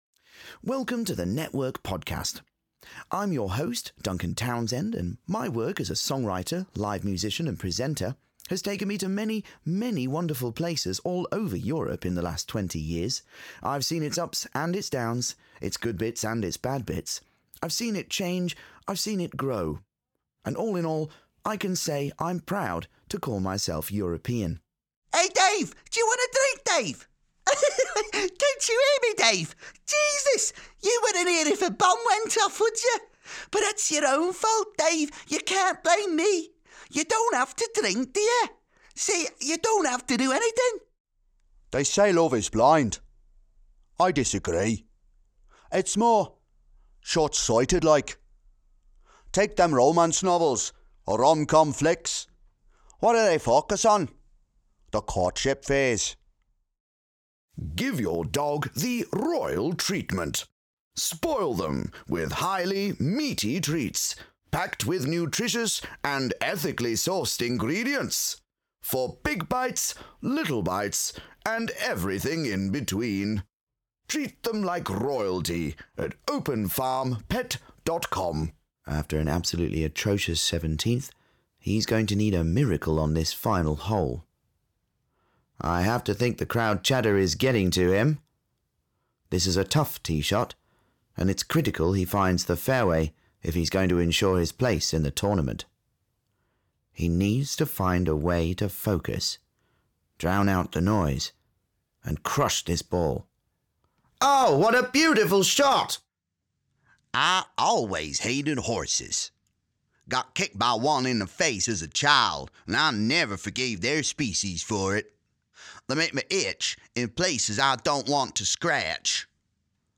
Demo
Young Adult, Adult
Has Own Studio